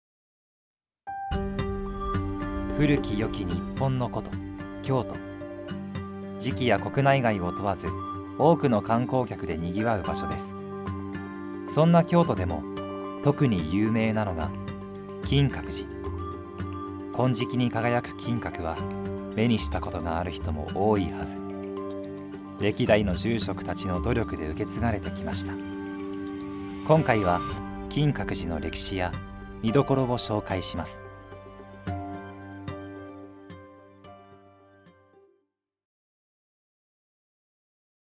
ナレーションA